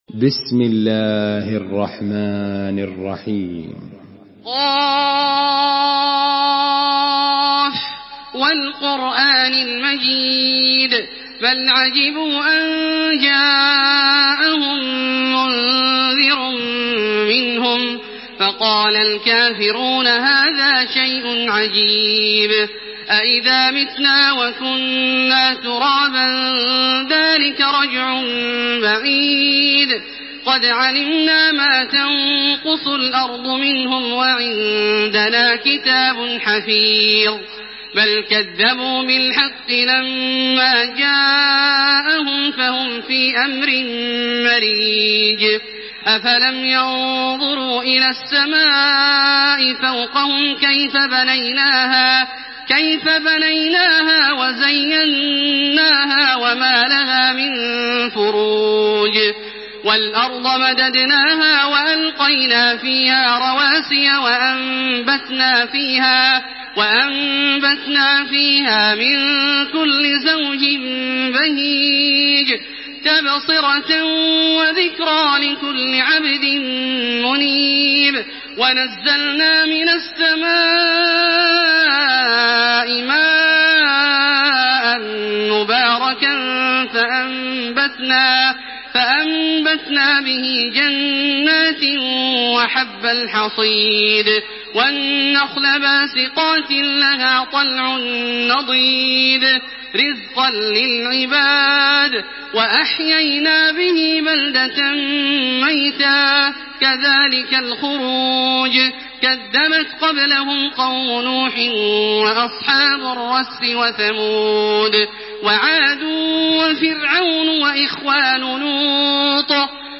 Surah Qaf MP3 in the Voice of Makkah Taraweeh 1428 in Hafs Narration
Murattal